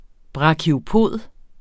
Udtale [ bʁɑkioˈpoˀð ]